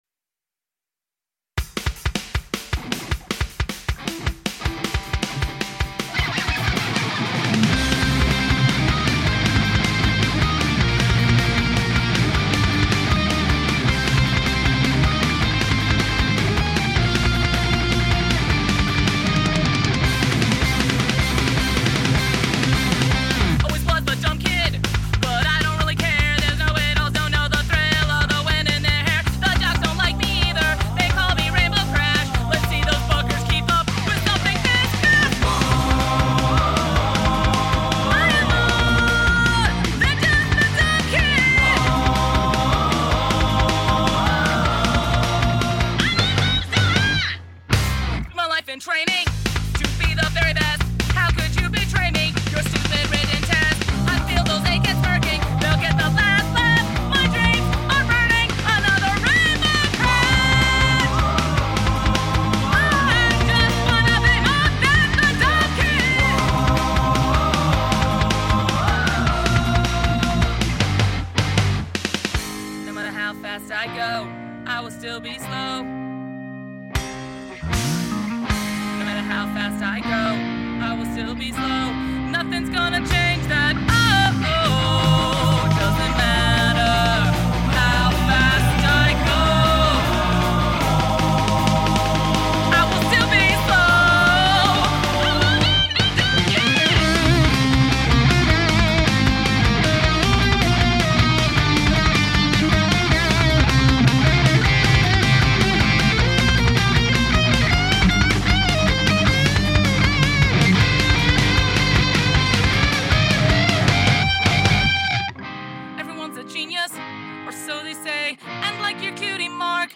Guitar, Bass, Drums
Lead Vocals